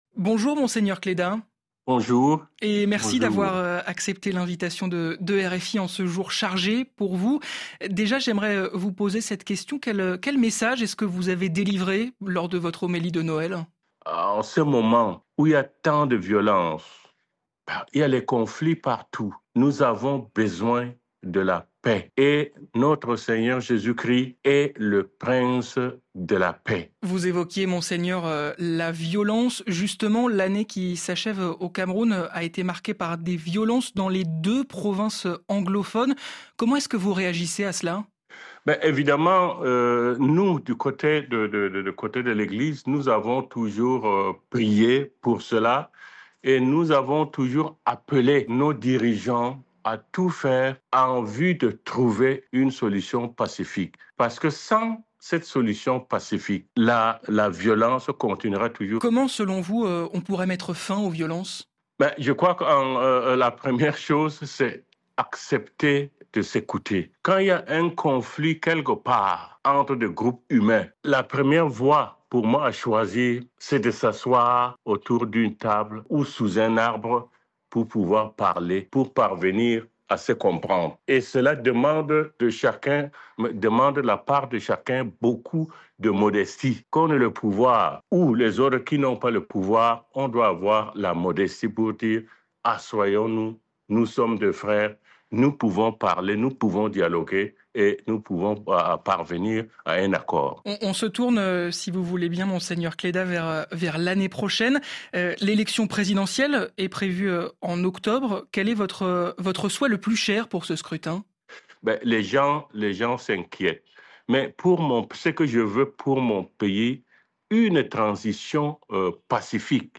Pour l’occasion, on se rend au Cameroun et on fait un tour d’horizon de la situation dans le pays, ainsi que des défis qui l’attendent pour cette nouvelle année. On parle des élections présidentielles à venir et de la santé de Paul Biya avec Samuel Kleda, l’archevêque de Douala, sur RFI.